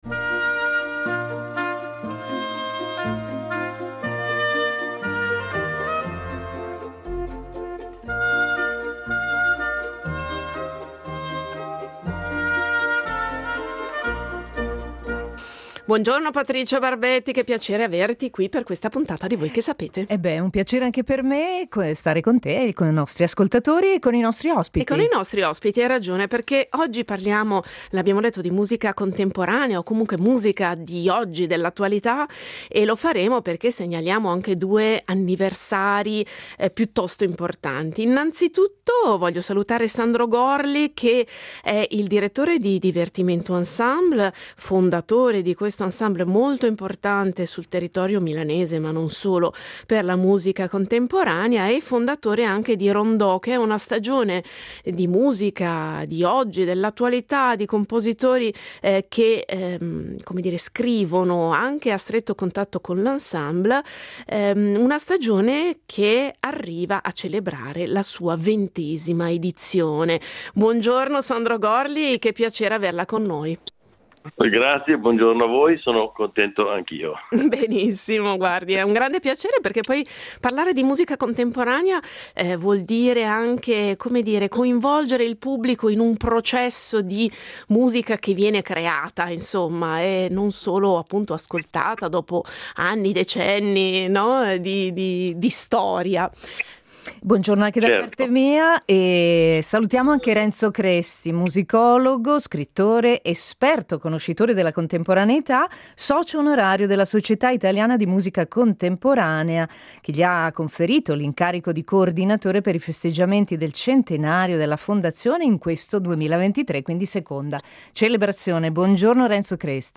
musicologo, scrittore, esperto conoscitore della contemporaneità